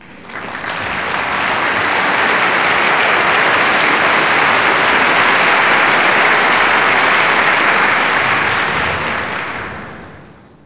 clap_029.wav